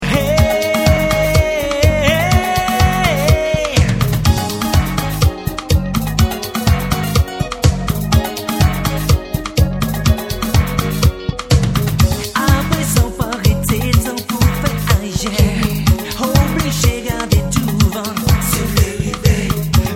Chant
Basse
Guitares
Claviers